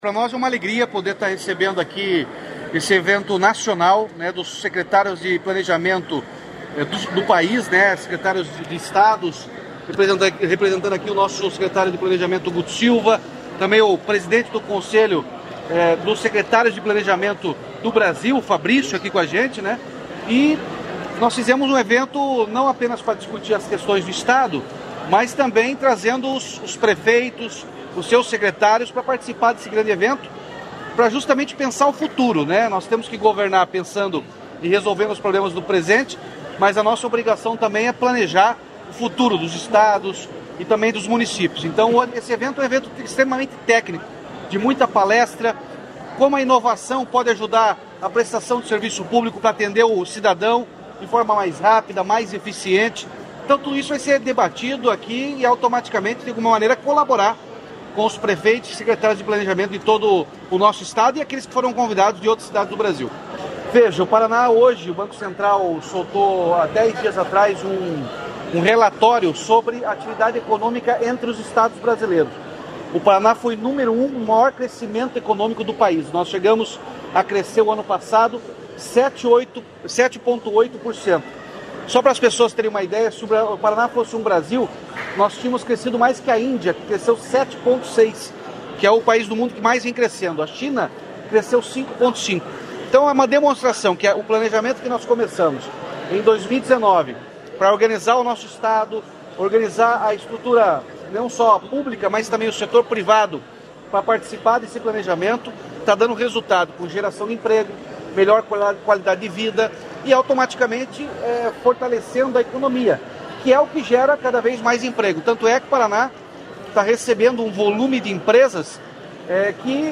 Sonora do governador Ratinho Junior sobre o Fórum Estadual de Gestores Públicos, que acontece em Foz